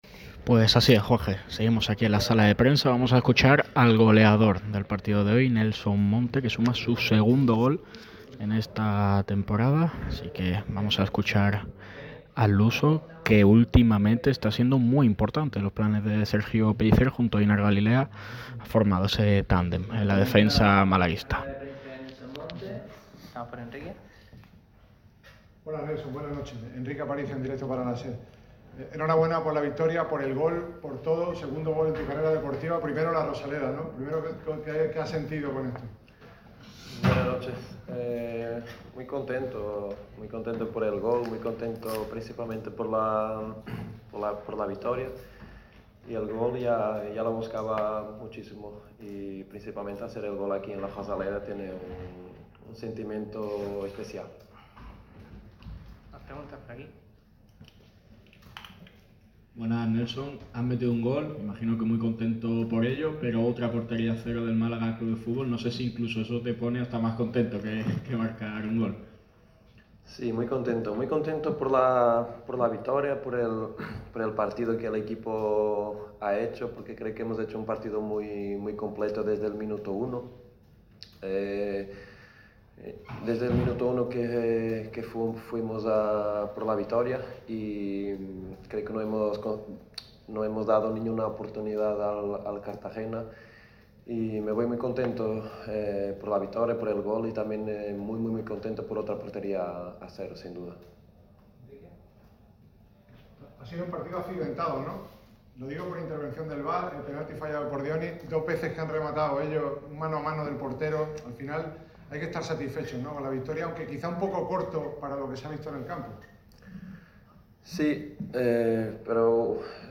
El defensa del Málaga CF, Nelson Monte, ha comparecido en rueda de prensa ante los medios tras la victoria del equipo en casa ante el FC Cartagena.